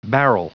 Prononciation du mot barrel en anglais (fichier audio)